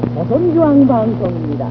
1. Interval signal
チャイム : 「金日成将軍の歌」 の最初の部分 MIDI file